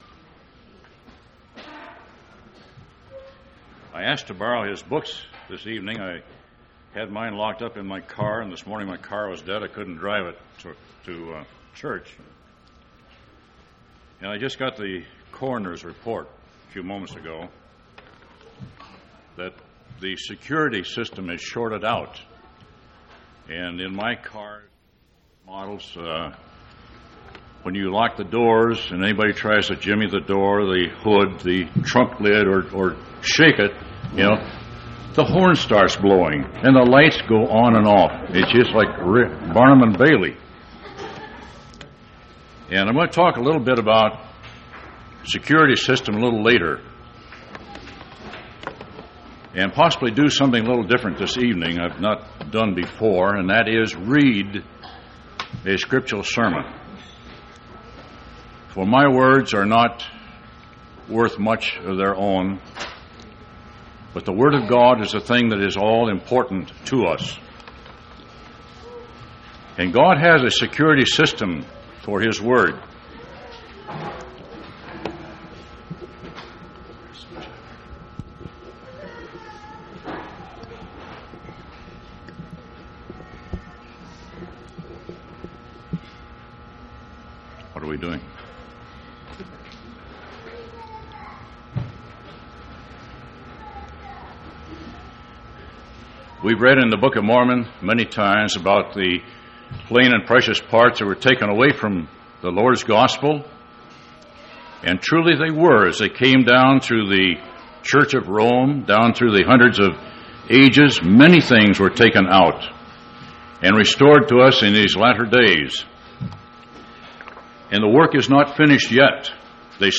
4/3/1989 Location: Temple Lot Local (Conference) Event: General Church Conference